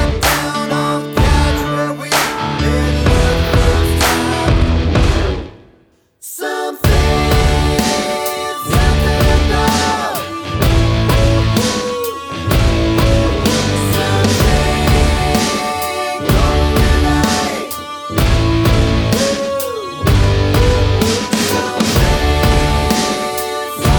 No Guitars Pop